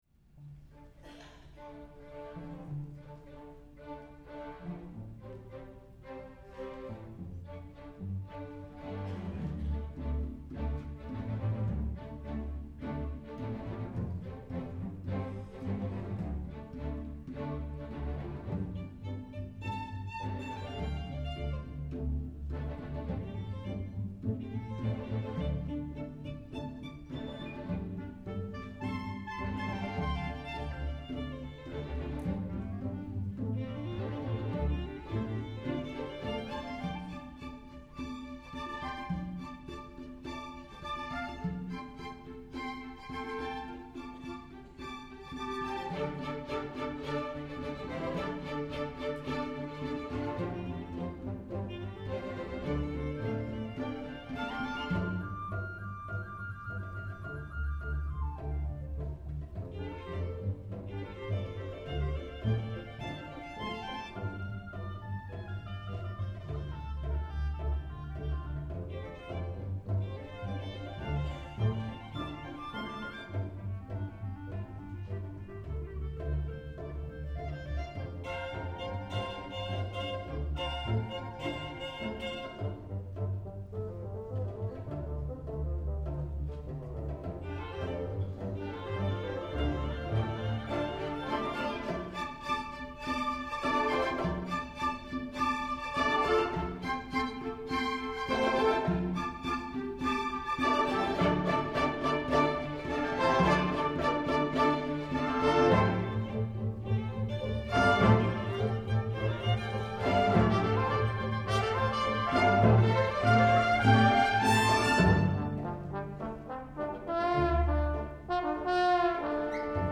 for Violin and Orchestra (1995)
violin
Open harmonies of fourths and fifths predominate.
But the rhythms border on jazz.